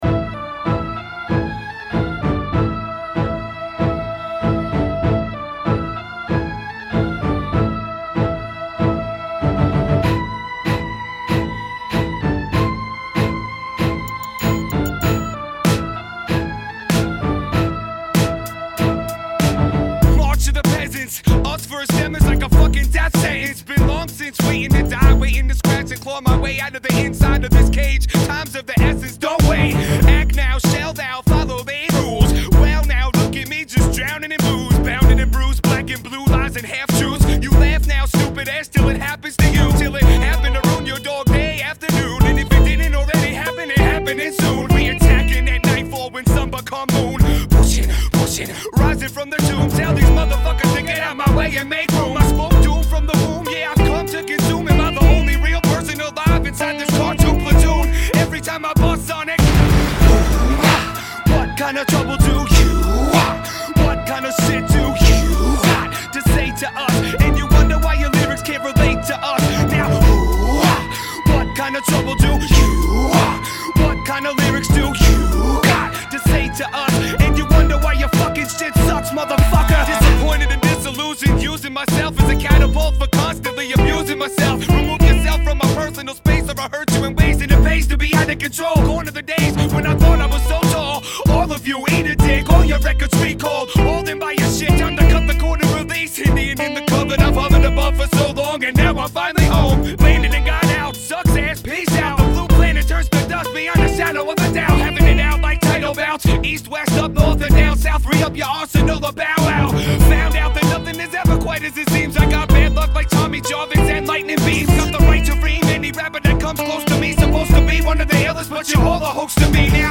New (old ass) demo up for download in the Media section, or